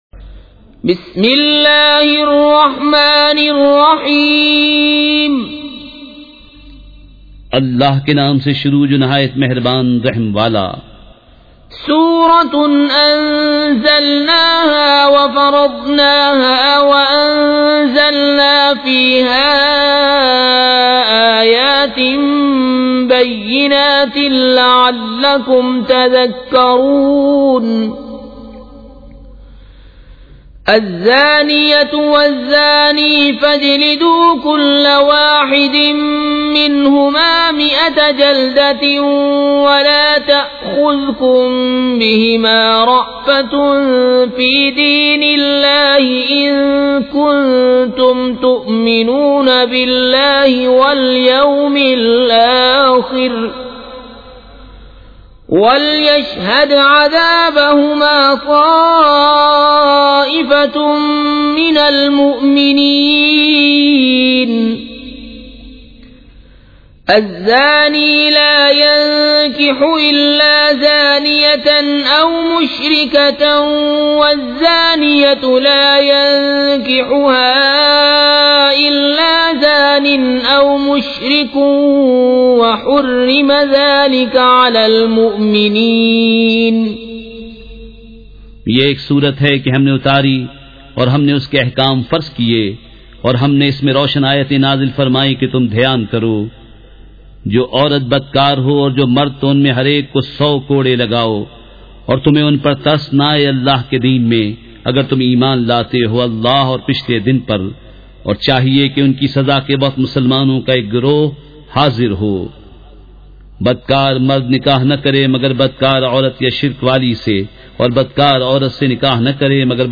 سورۃ النور مع ترجمہ کنزالایمان ZiaeTaiba Audio میڈیا کی معلومات نام سورۃ النور مع ترجمہ کنزالایمان موضوع تلاوت آواز دیگر زبان عربی کل نتائج 6460 قسم آڈیو ڈاؤن لوڈ MP 3 ڈاؤن لوڈ MP 4 متعلقہ تجویزوآراء